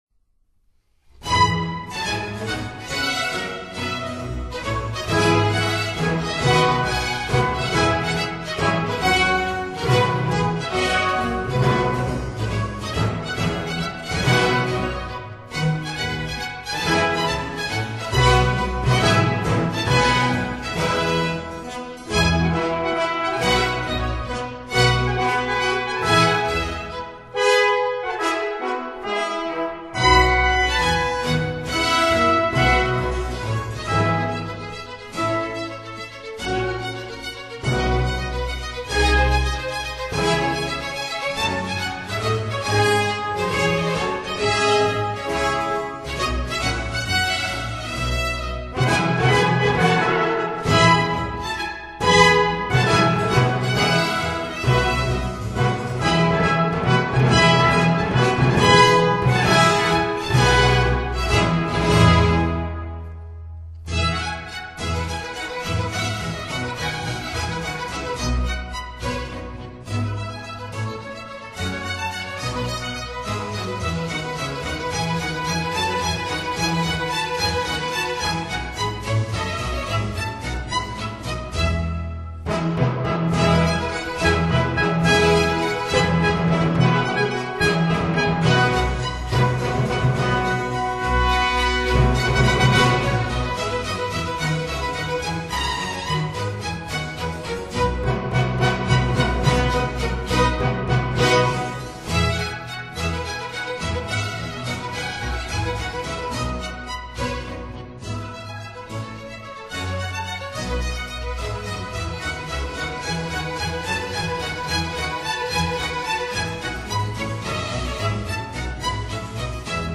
歌剧